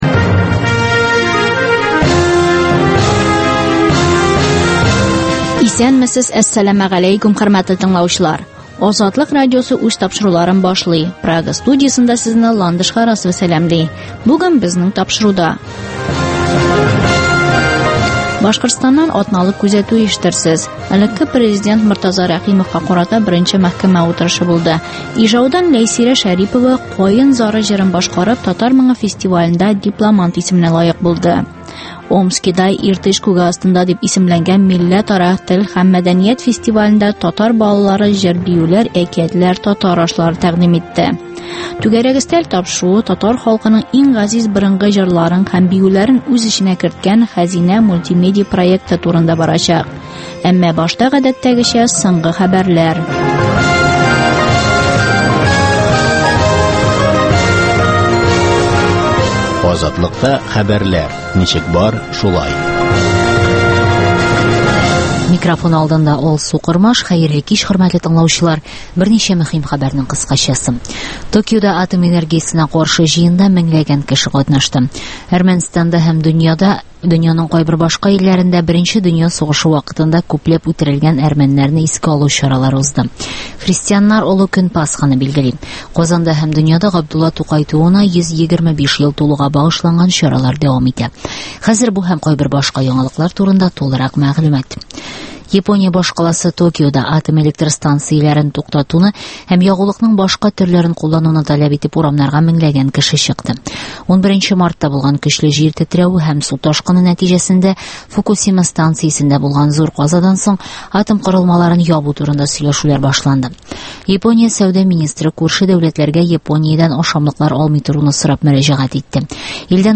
Азатлык узган атнага күз сала - соңгы хәбәрләр - башкортстаннан атналык күзәтү - татар дөньясы - түгәрәк өстәл сөйләшүе